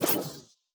Cybernetic Technology Affirmation 11.wav